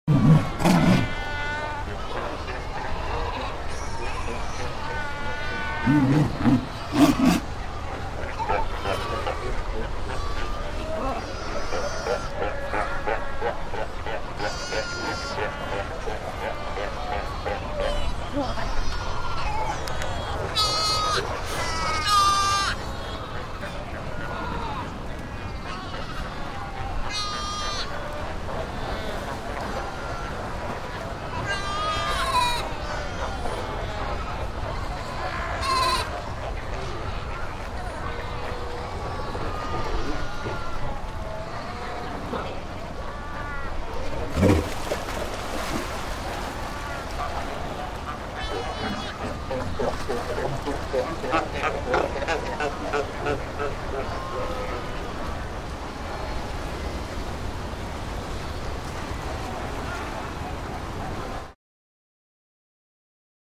دانلود آهنگ حیوان آبی 8 از افکت صوتی انسان و موجودات زنده
جلوه های صوتی
دانلود صدای حیوان آبی 8 از ساعد نیوز با لینک مستقیم و کیفیت بالا